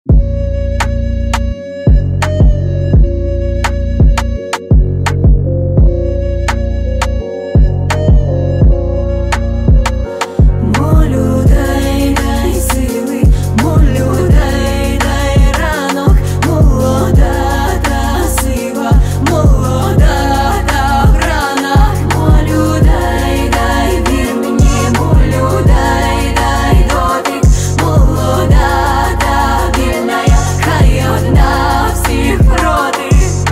грустные
спокойные
красивая мелодия
красивый женский вокал